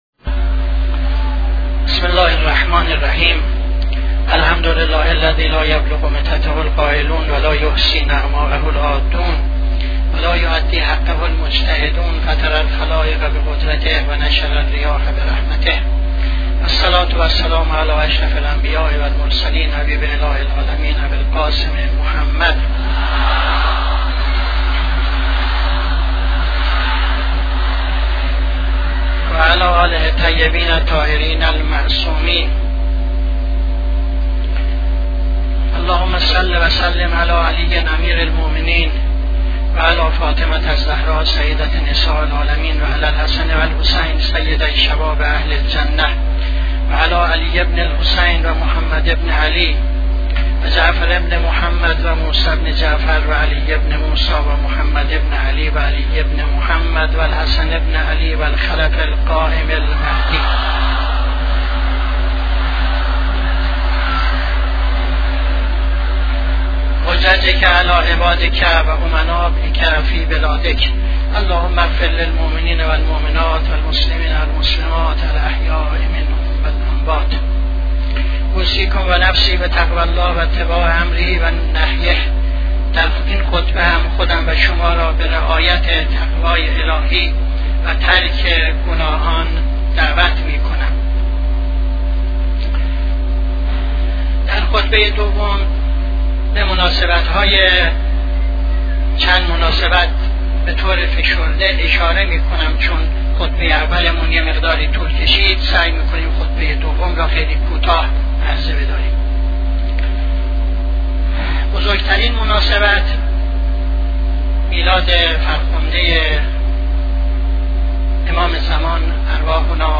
خطبه دوم نماز جمعه 28-09-76